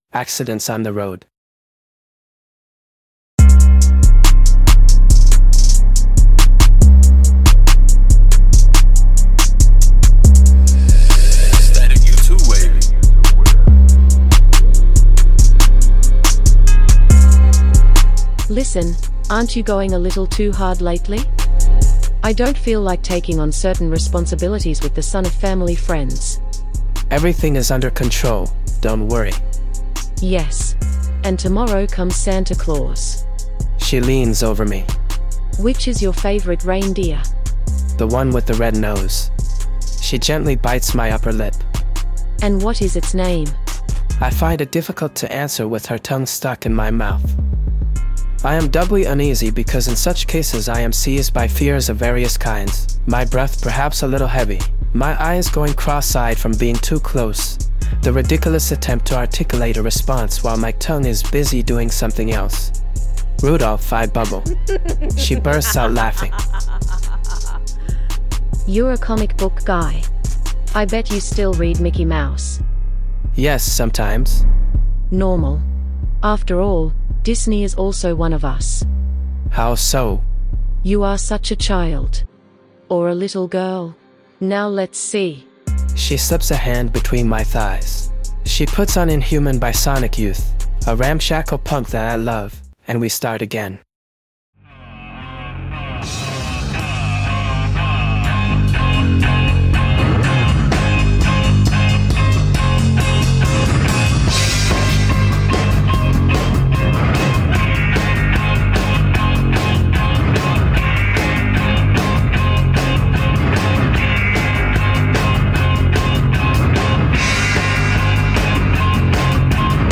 During the episode you can listen to the following songs: "Galaxy" and "Forbidden Chamber" by Static Bandz, whom we thank for the permission; "Inhuman" by Sonic Youth; "Old Age" (bootleg) by Kurt Cobain.